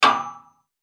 На этой странице собраны звуки работающего радиатора — от мягкого потрескивания до монотонного гула.
Звук одиночного металлического стука по батарее